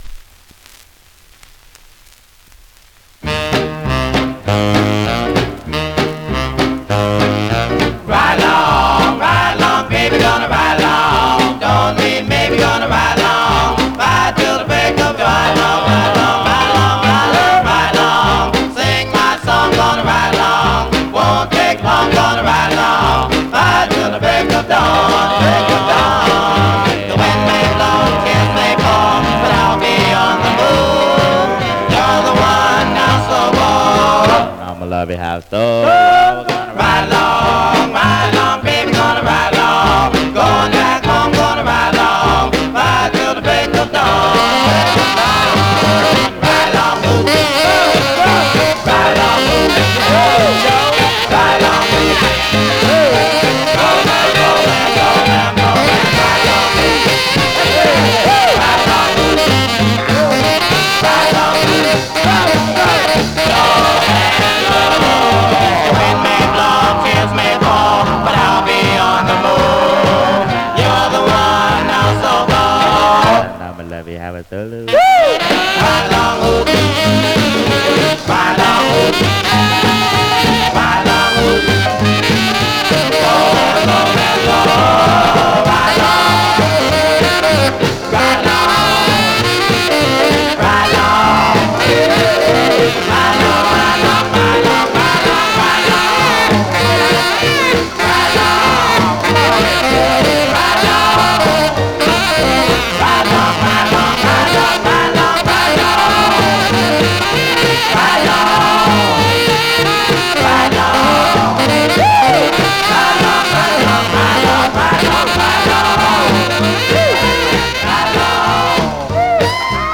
Condition Surface noise/wear Stereo/mono Mono
Male Black Group